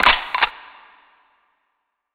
Perc 4.wav